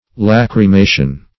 \Lach`ry*ma"tion\